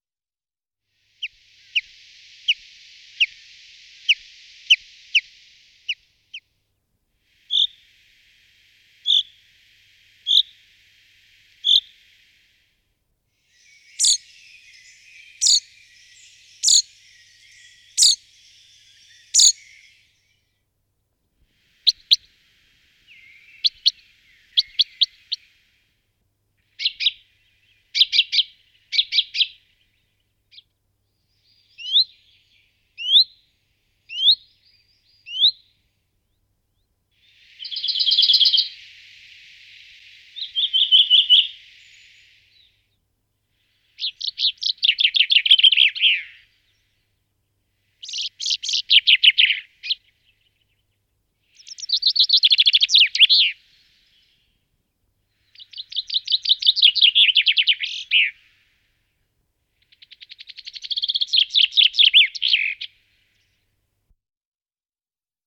Fringuello
Fringilla coelebs
Richiamo un chiaro ‘pink’ e in volo ‘giuup’. Canto sonoro e in calando, vagamente simile a quello del Luì grosso, ma meno dolce e con più trilli.
Fringuello.mp3